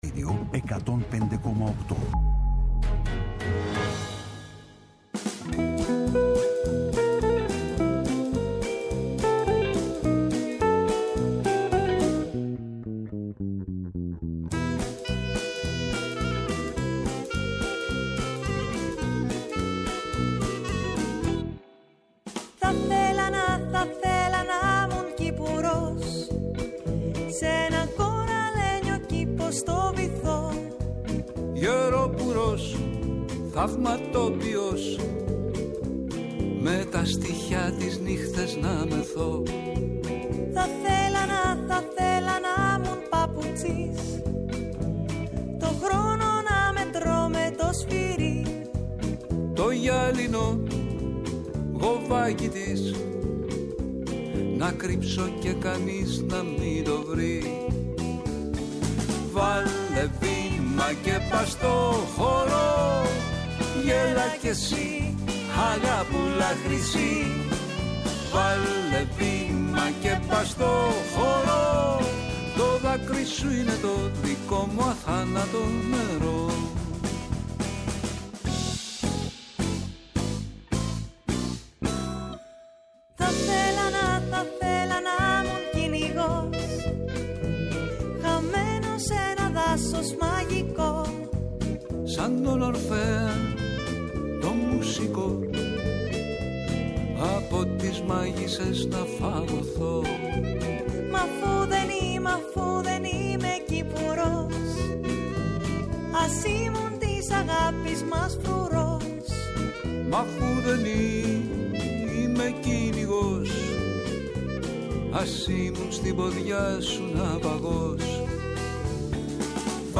-Ο Αθανάσιος Μαμάκος, δήμαρχος Λάρισας, για εκδηλώσεις Τσικνοπέμπτης